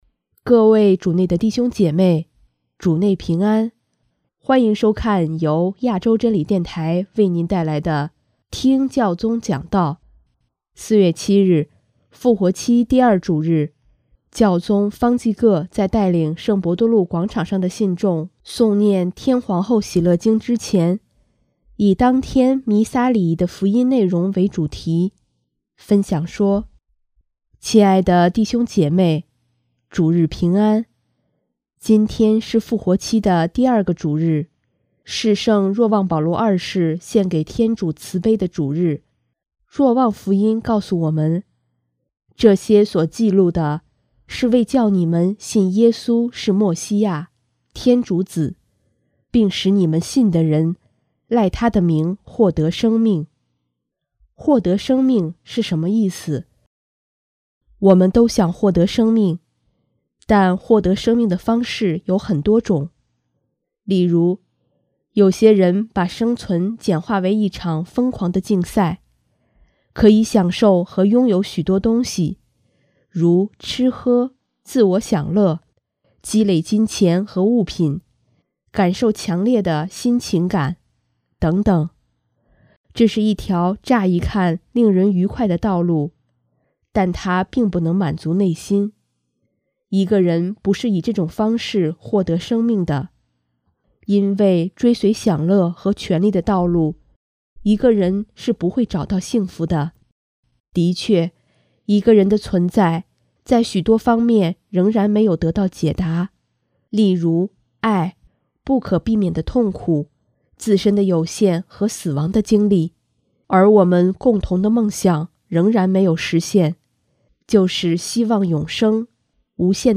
4月7日，复活期第二主日，教宗方济各在带领圣伯多禄广场上的信众诵念《天皇后喜乐经》之前，以当天弥撒礼仪的福音内容为主题，分享说：